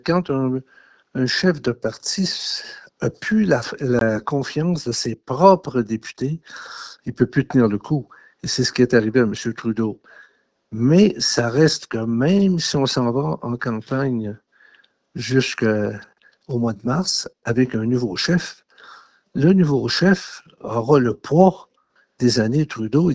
En entrevue, le député de Bécancour-Nicolet-Saurel, a expliqué que son remplaçant aura beaucoup de travail devant lui.